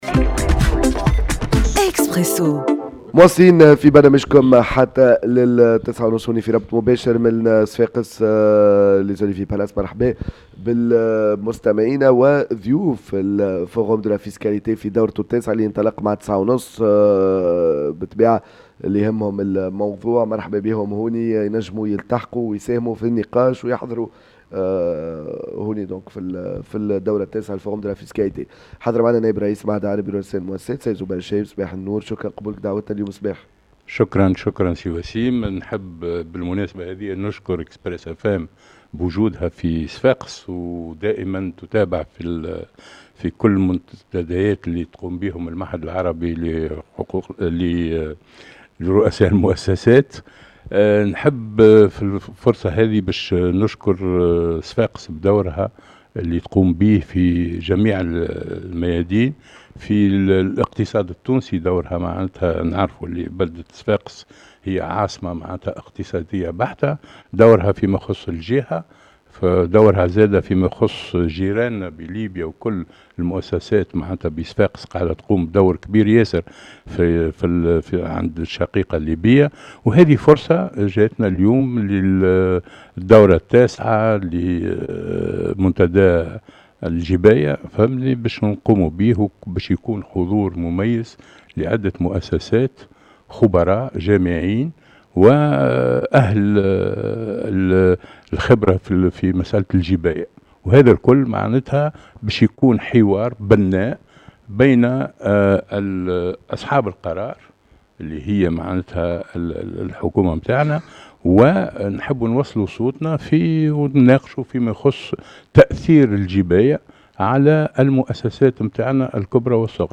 مباشرة من منتدى الجباية في نسخته التاسعة